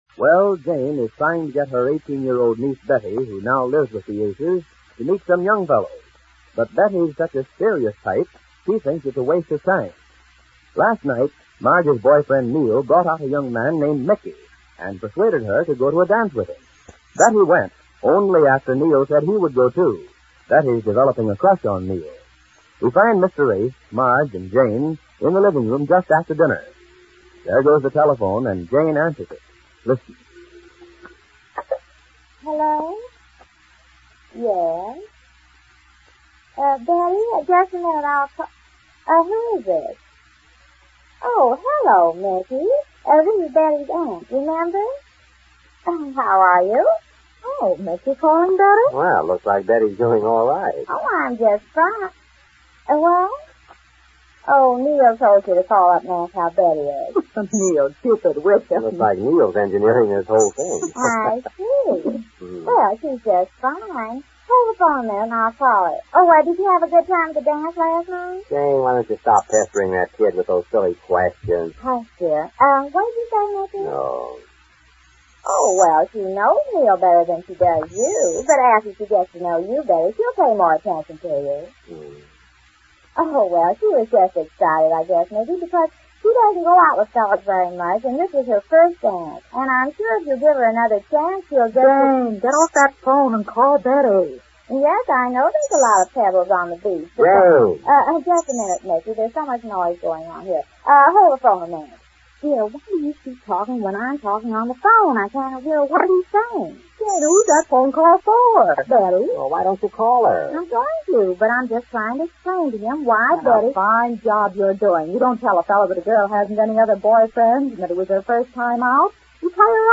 Easy Aces Radio Program, Starring Goodman Aiskowitz and Jane Epstein-Aiskowitz